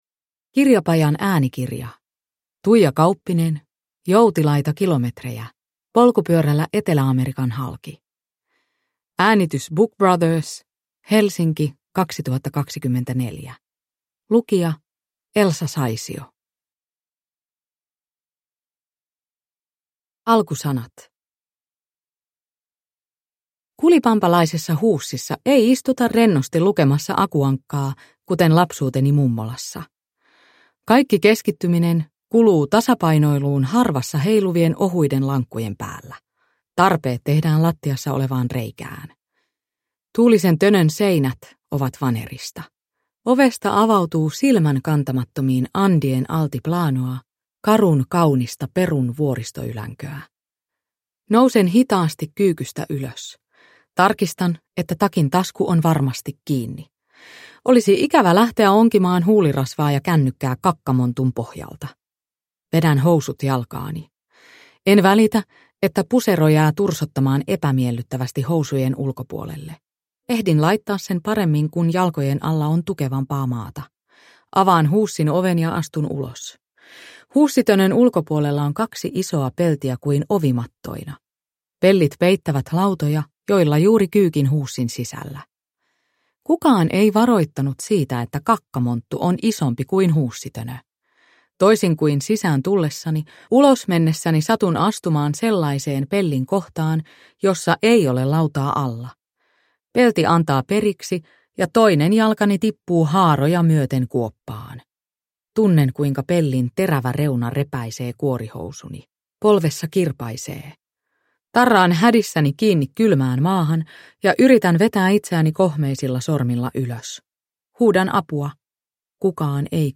Joutilaita kilometrejä – Ljudbok